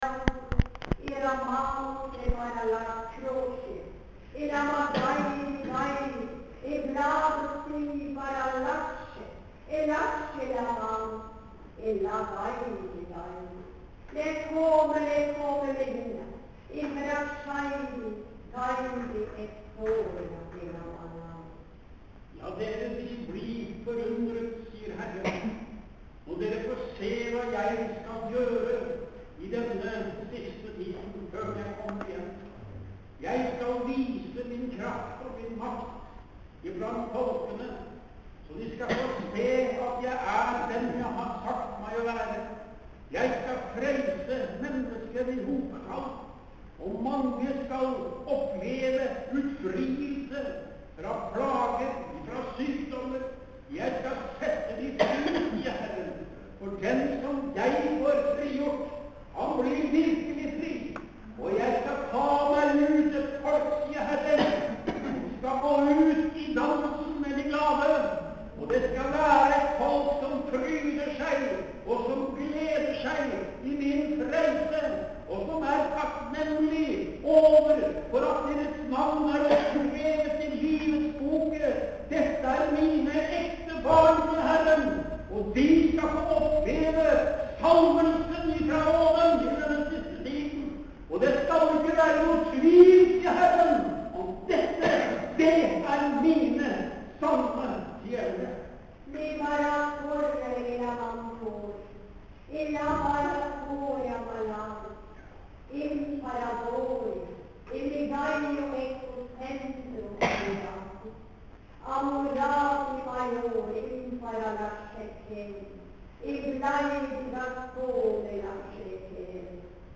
Tyding av tungetale:
Maranata søndag  17.8.09.